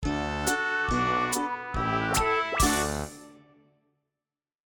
Plays end of the track